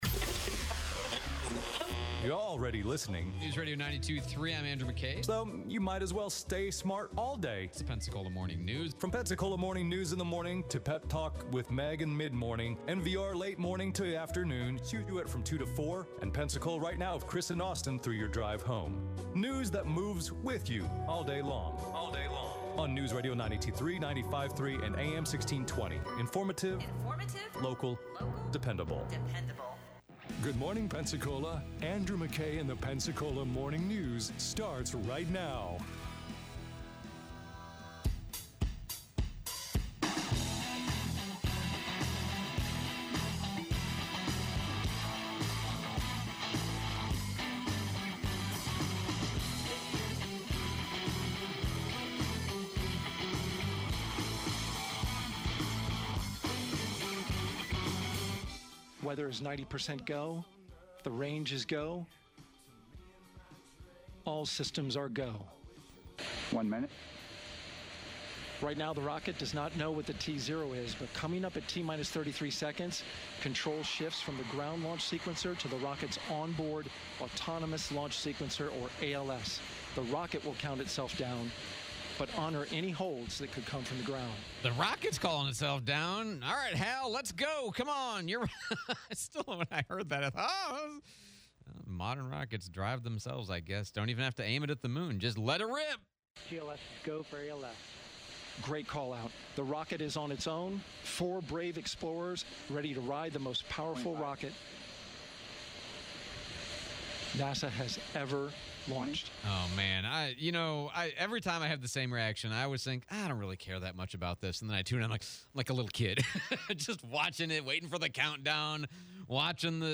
Artemis II launch, April Fools jokes, PPD Chief Eric Winstrom interview